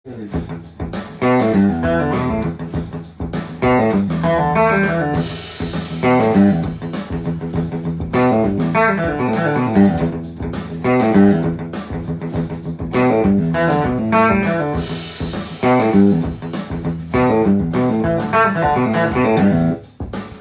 慣れてきたら、こんな感じにメトロノームやドラム音源にあわせて適当に
Emペンタトニックスケールでプリングを使って弾いても楽しそうですね。